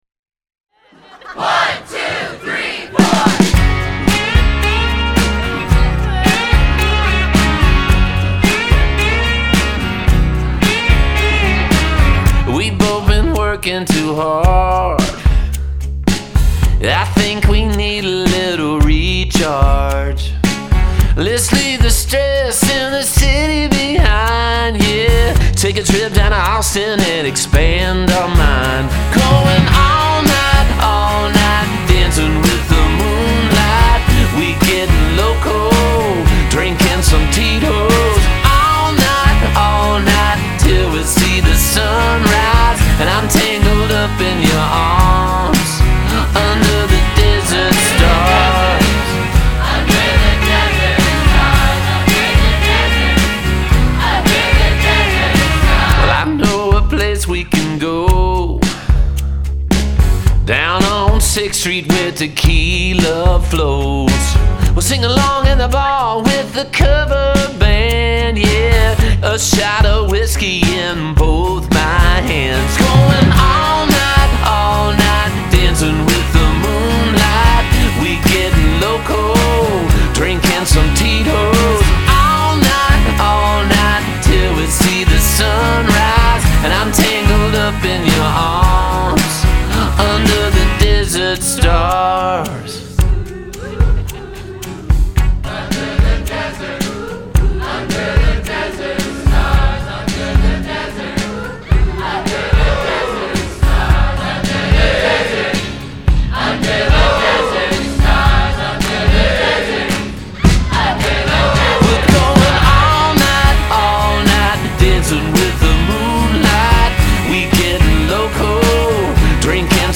Song from Kevin Griffin Closing Session